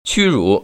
屈辱[qūrǔ]